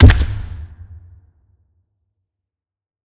KS_Barefoot_2.wav